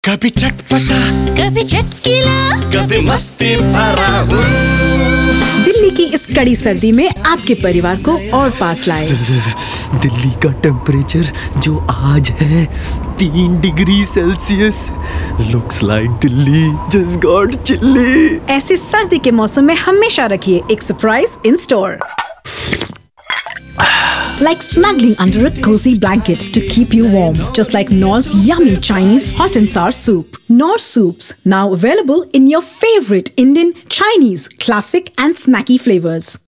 Indian, Hindi, Punjabi, Neutal Accent, Friendly, Sexy, Conversational
Sprechprobe: Industrie (Muttersprache):